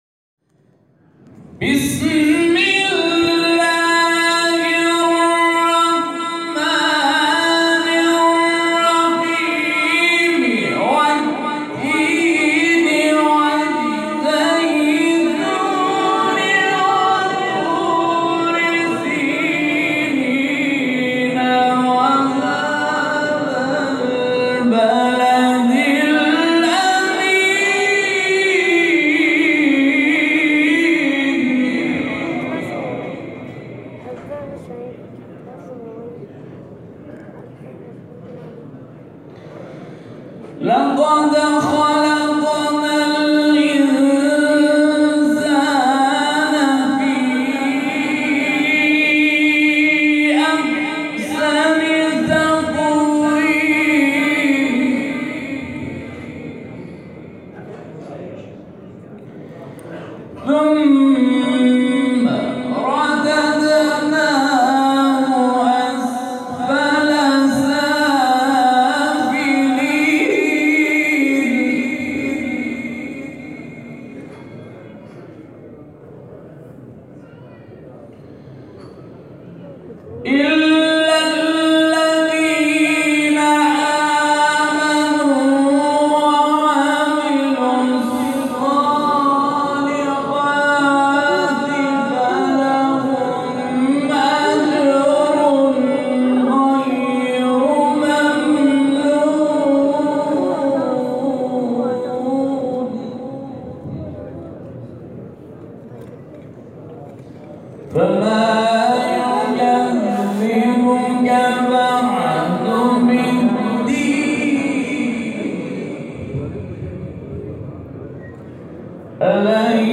گروه شبکه اجتماعی: فرازهای صوتی از تلاوت قاریان بنام و ممتاز کشور را می‌شنوید.